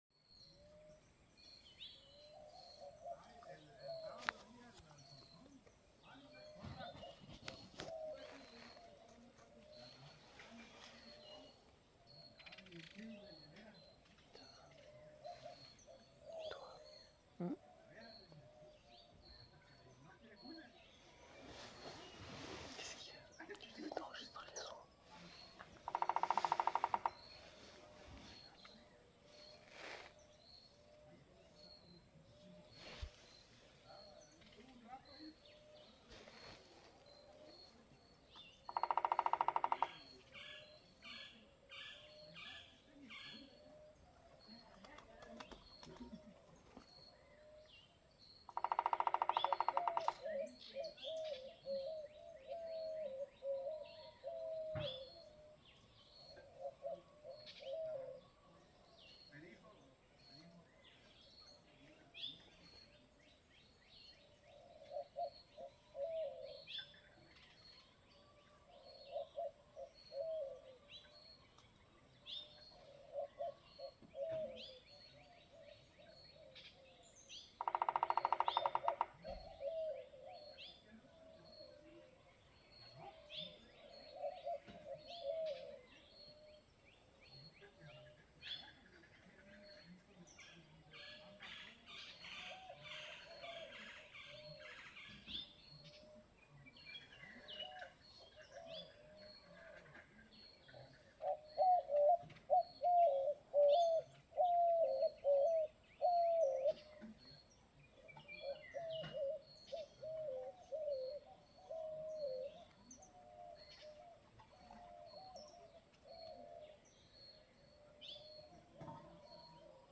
La Sierra la Laguna est une zone naturelle protégée ou vivent des dizaines d’espèces d’oiseaux et nous étions réveillés tous les matins par leurs chants et par le bruit des pics-verts.
birds-sound-Rancho-San-Dionisio-1.mp3